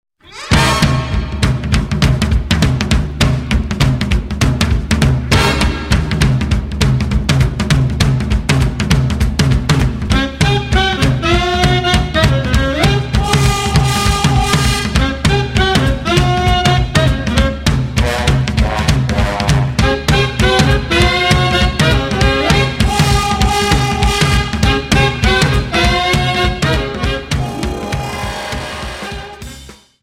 Dance: Quickstep 50